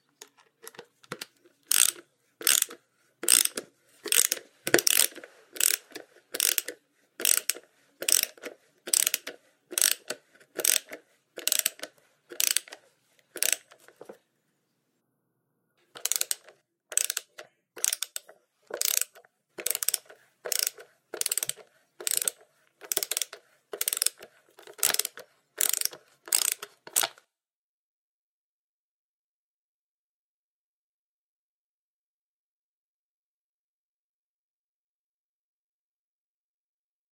Звуки отвертки
Звук трещотки профессиональной отвертки с трещоточным механизмом